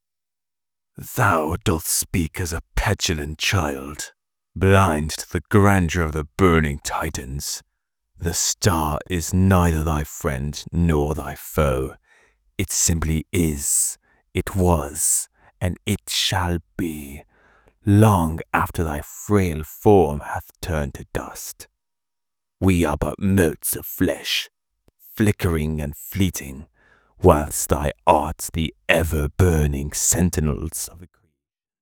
Male | Teenage Characters / Videogames